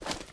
STEPS Dirt, Run 19.wav